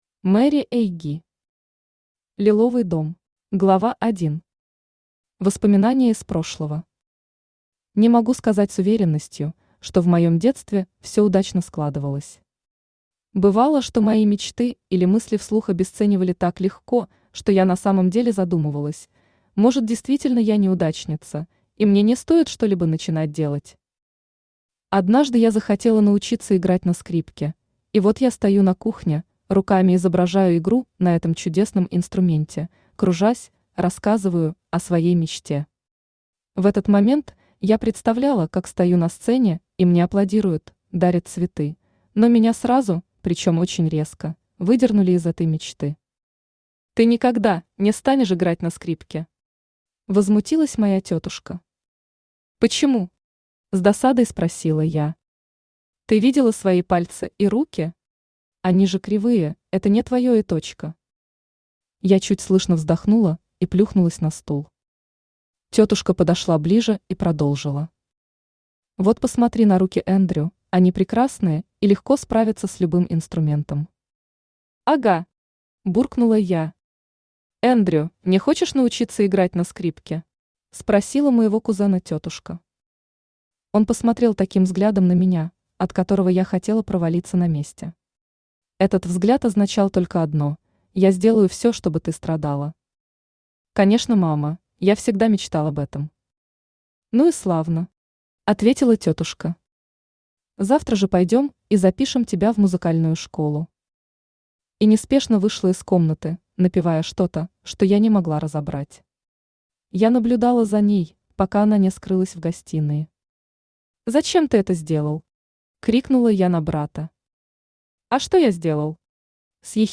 Аудиокнига Лиловый дом | Библиотека аудиокниг
Aудиокнига Лиловый дом Автор MaryAg Читает аудиокнигу Авточтец ЛитРес.